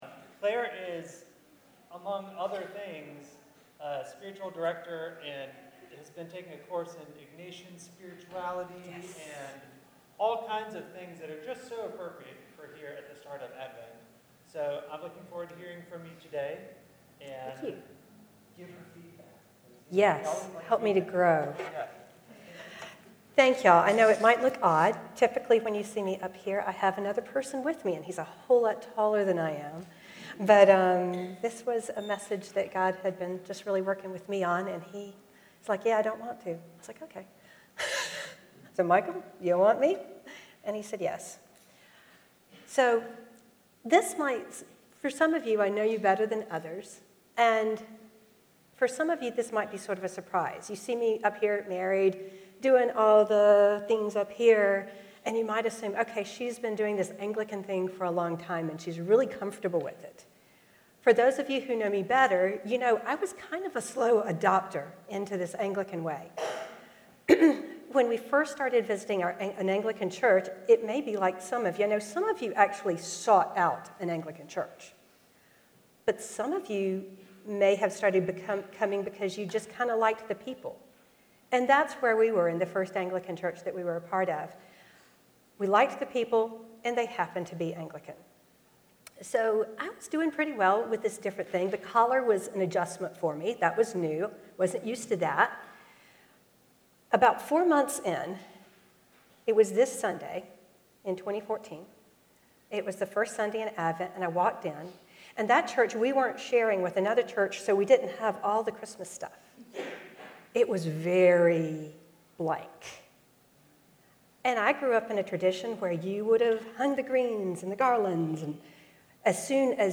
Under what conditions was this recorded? message from the First Sunday of Advent, November 30, 2025.